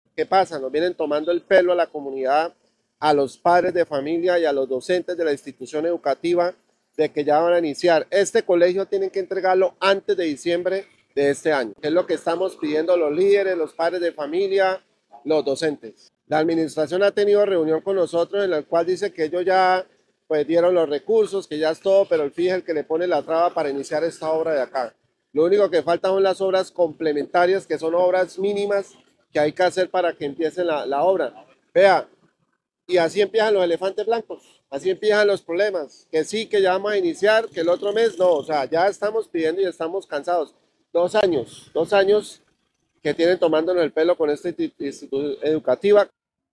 Leonardo León, edil de la comuna 4